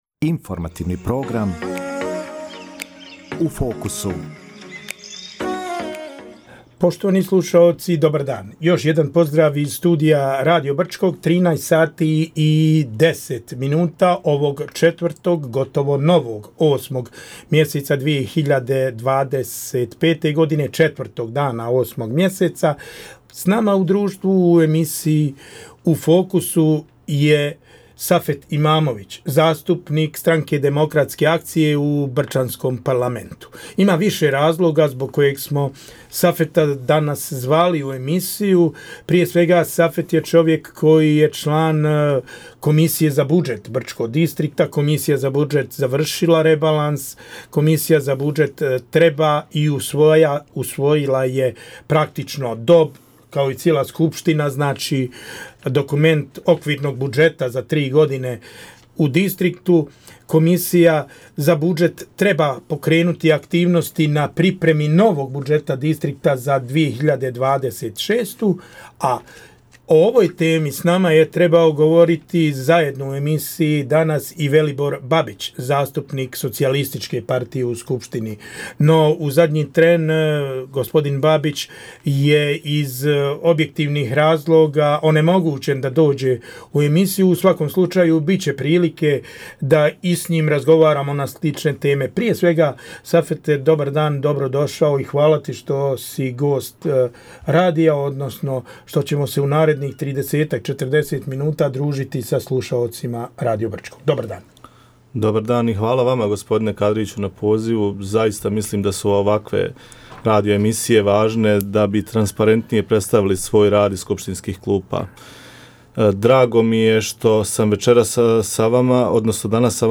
U emisiji “U fokusu” razgovarali smo sa zastupnikom Safetom Imamovićem o rebalansu budžeta i pripremi usvajanja novog budžeta za 2026. godinu, te njegovim pokrenutim inicijativama.